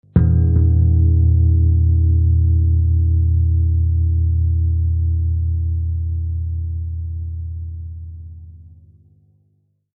無伴奏ギター独奏
除夜の鐘とか、こんな